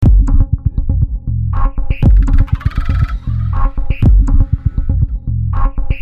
身体打击
描述：稳定的脉冲器
标签： 120 bpm Electro Loops Drum Loops 1.01 MB wav Key : Unknown
声道立体声